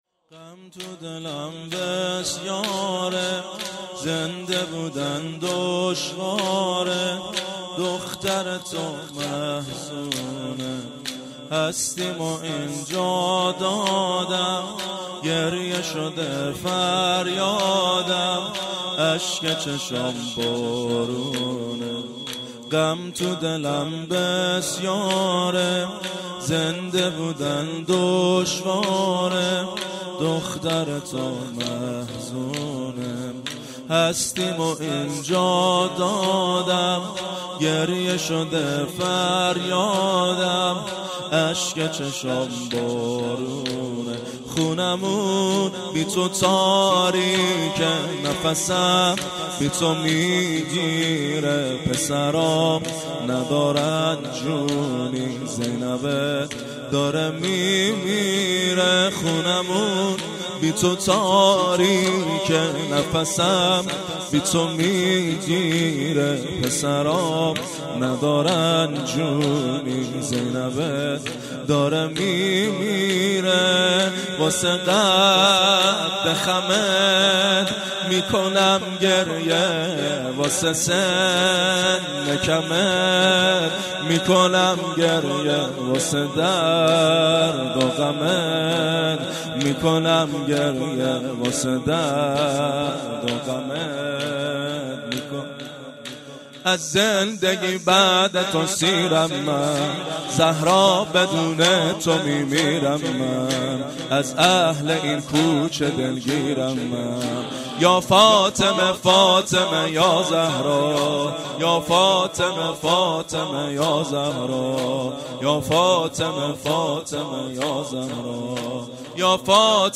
هیئت زواراباالمهدی(ع) بابلسر
0 0 زمینه - غم تو دلم بسیاره
شب سوم ویژه برنامه فاطمیه دوم ۱۴۳۹